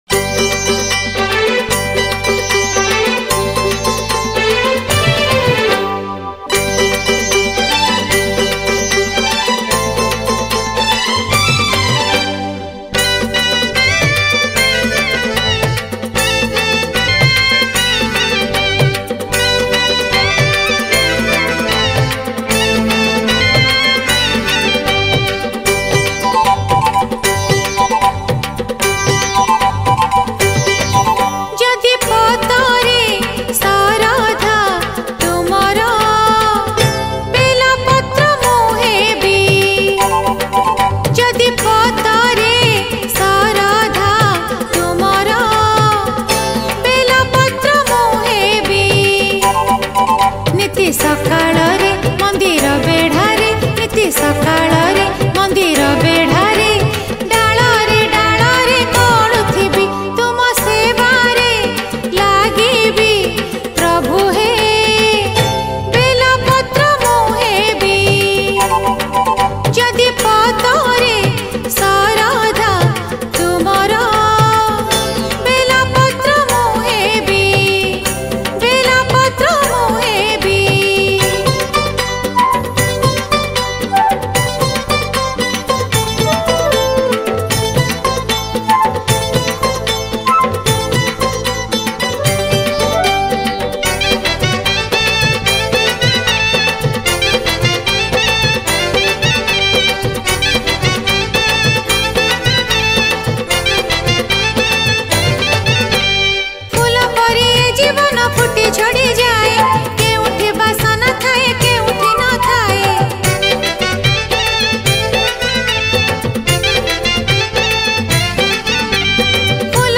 Jagara Special Odia Bhajan Song